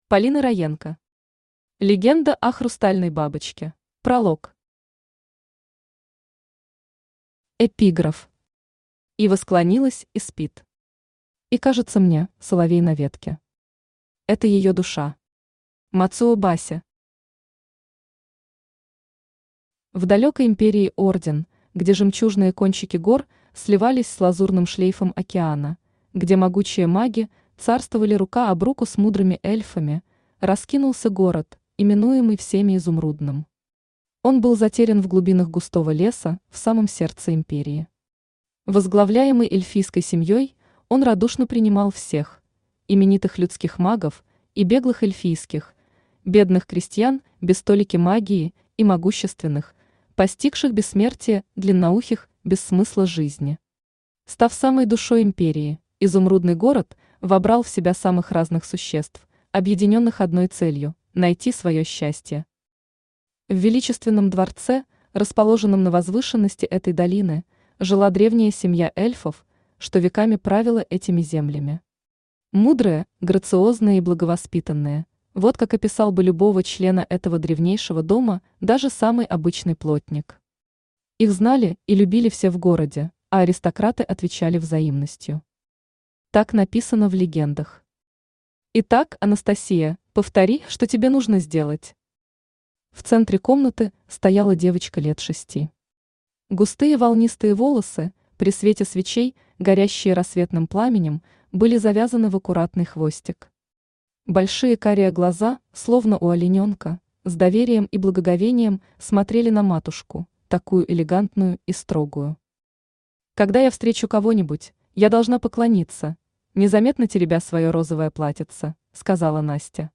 Аудиокнига Легенда о хрустальной бабочке | Библиотека аудиокниг
Aудиокнига Легенда о хрустальной бабочке Автор Полина Роенко Читает аудиокнигу Авточтец ЛитРес.